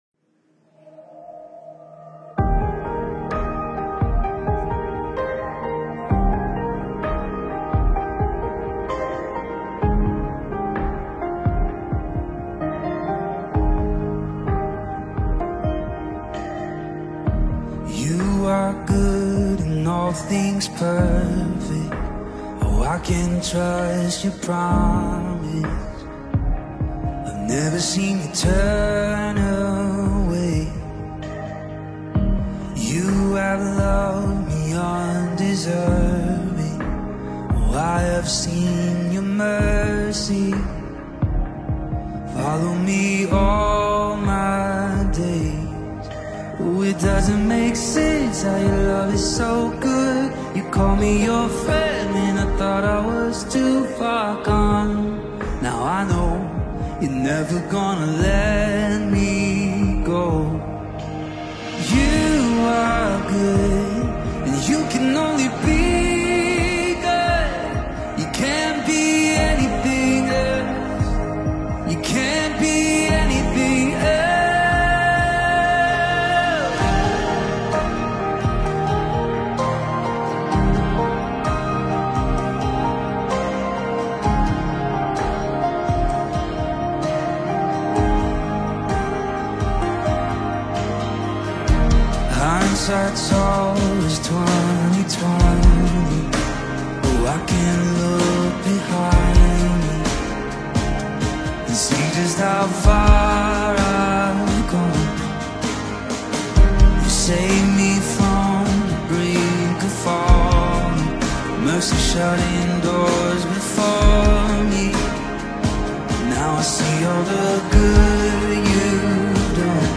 live song